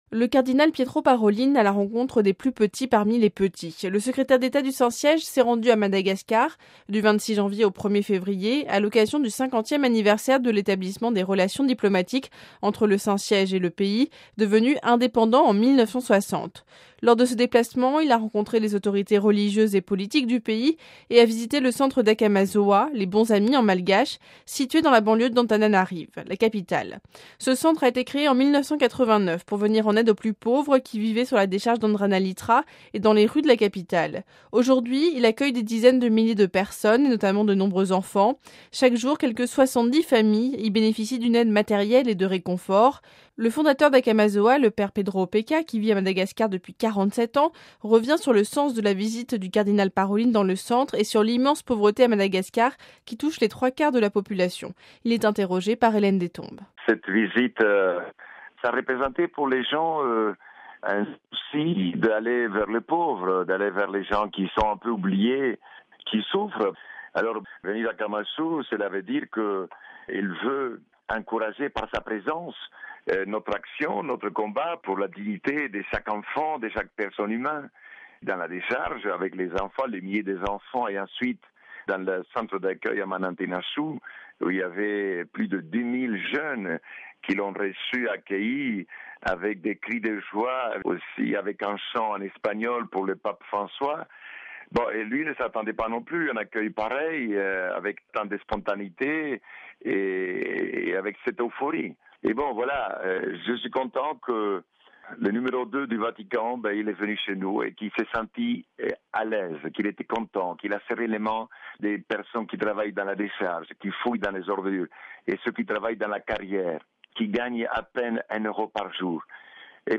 (RV) Entretien - Le cardinal Pietro Parolin est allé à la rencontre des plus petits parmi les petits.